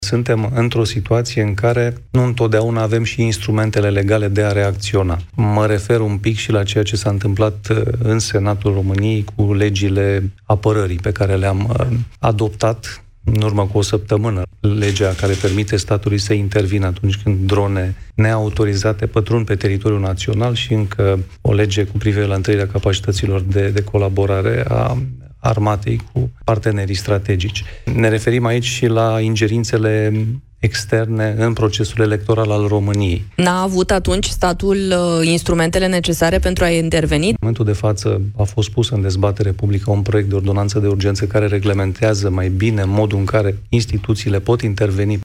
Declararea persona non grata de către MAE a ataşatului militar rus şi a asistentului acestuia este o decizie absolut justificată – spune, la Europa FM, președintele interimar al Senatului, Mircea Abrudean.